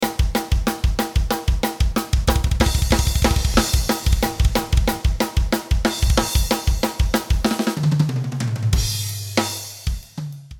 Triggers_Drums.mp3